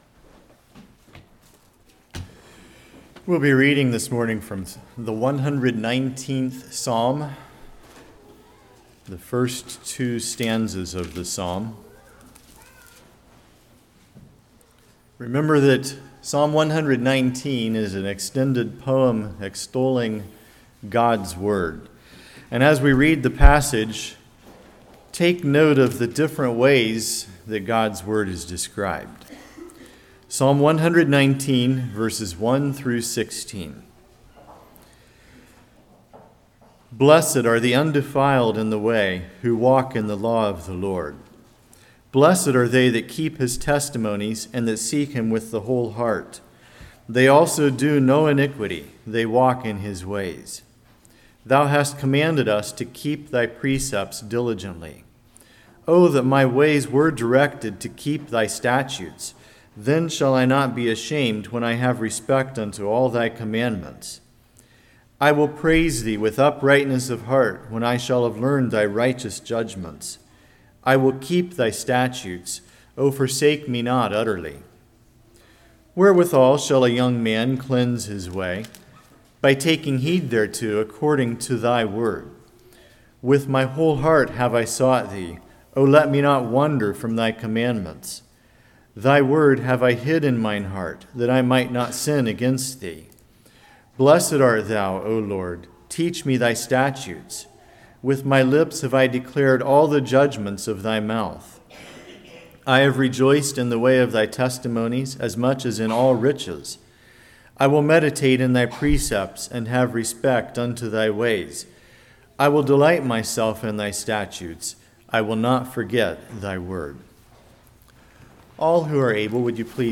Psalms 119:1-16 Service Type: Revival Are You Tired Of Doing All The Right Things?